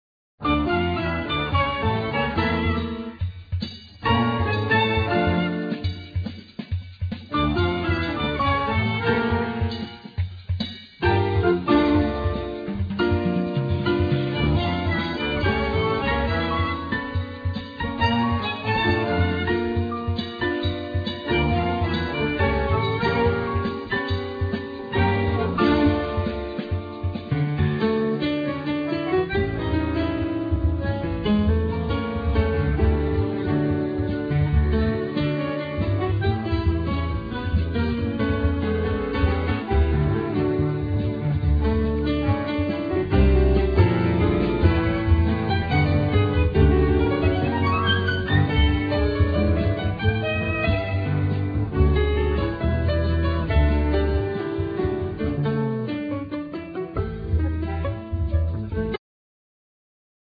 Piano
Bass
Drums
Bandneon
Violin
Sax